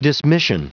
Prononciation du mot dismission en anglais (fichier audio)